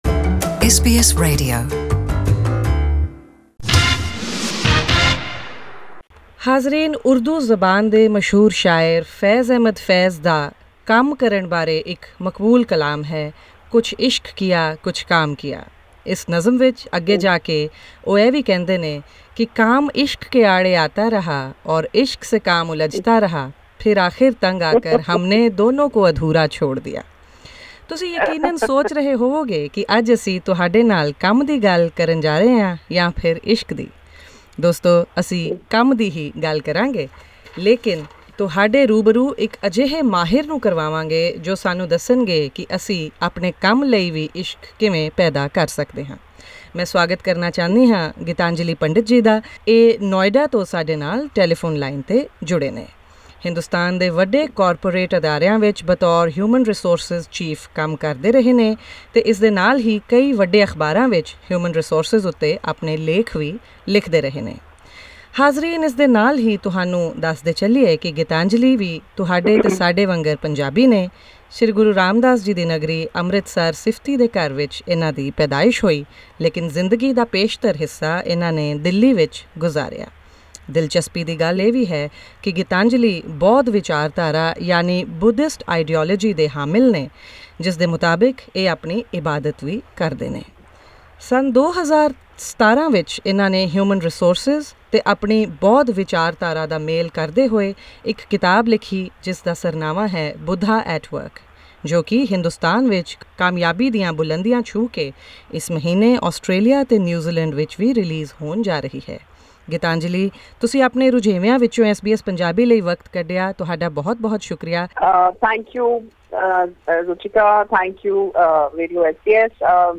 In an interview